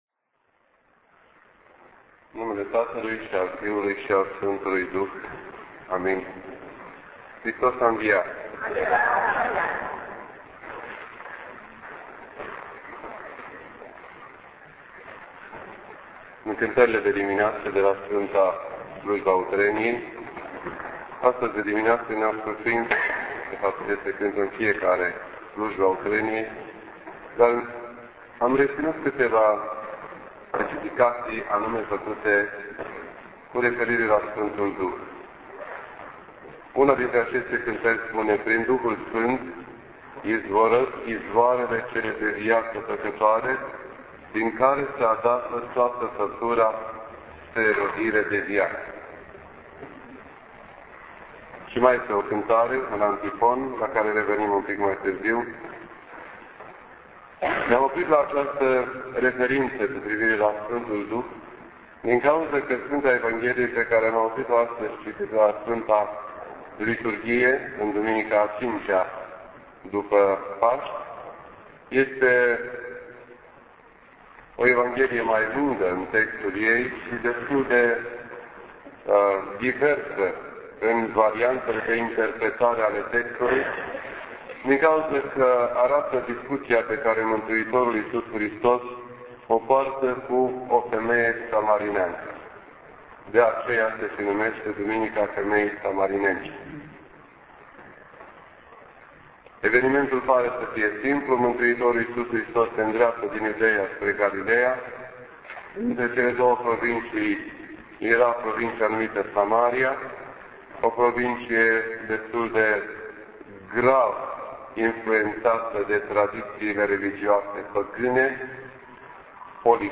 This entry was posted on Sunday, May 2nd, 2010 at 7:40 PM and is filed under Predici ortodoxe in format audio.